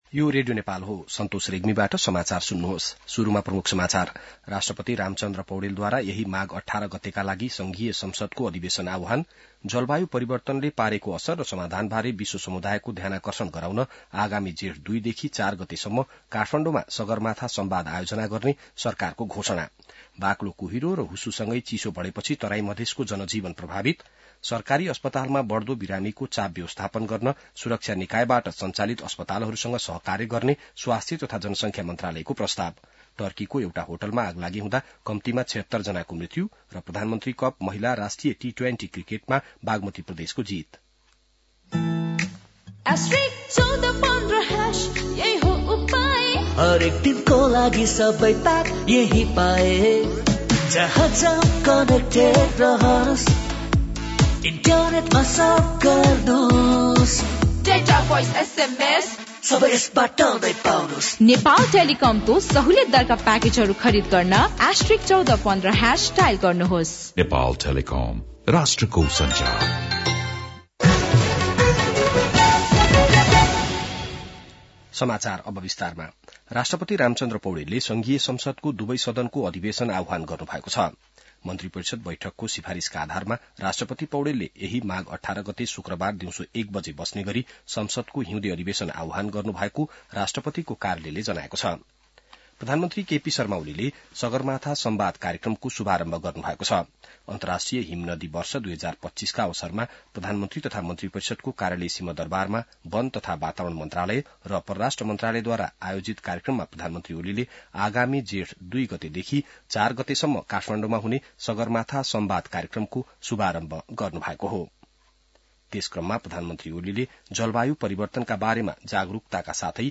बिहान ७ बजेको नेपाली समाचार : १० माघ , २०८१